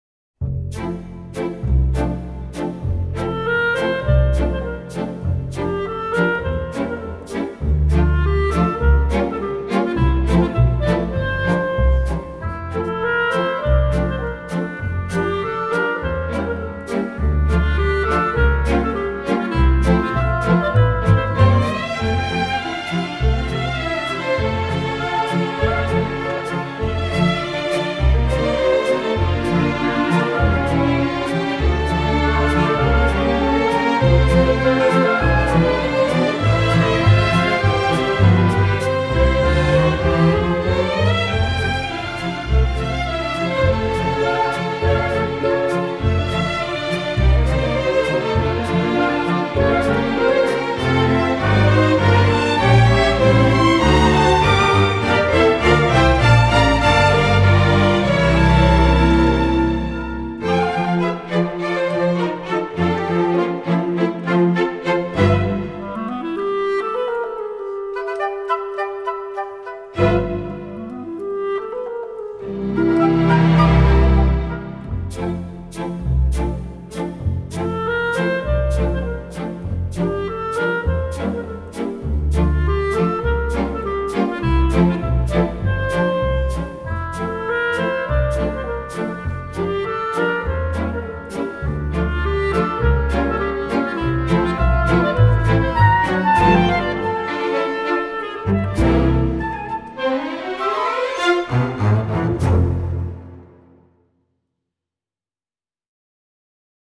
happy town background music